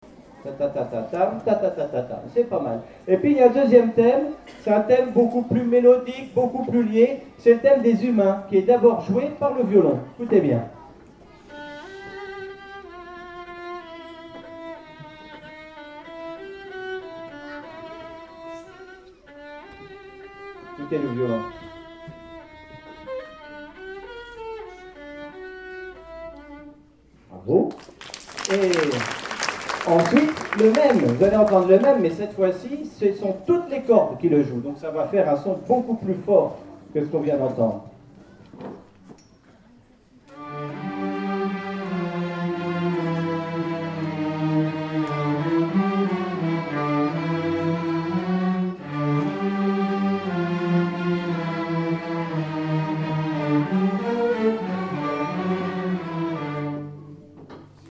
Album: Concert pédagogique 2011